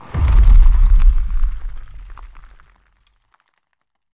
springboard_blowout.ogg